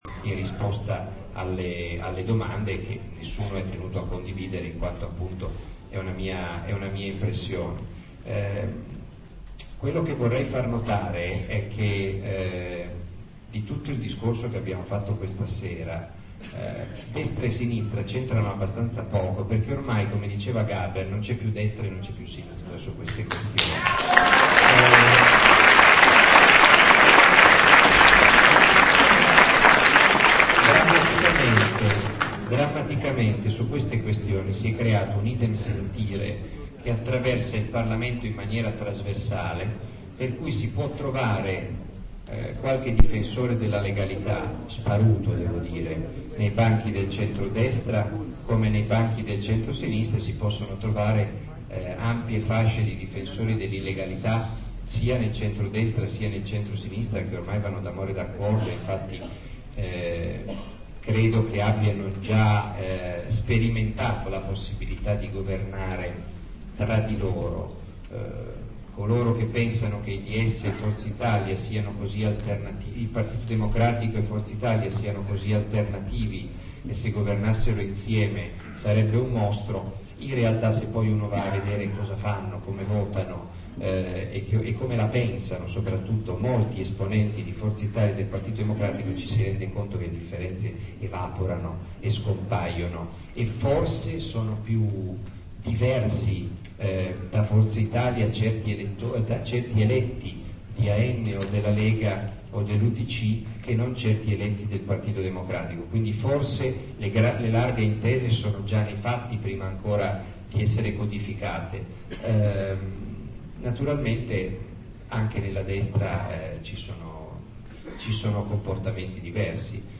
Un intervento di Marco Travaglio, registrato lo scorso 9 gennaio a Pescara, all'auditorium De Cecco, in occasione della presentazione del suo libro "Mani Sporche".
Marco Travaglio, Auditorium De Cecco, Pescara, 9 gennaio 2008.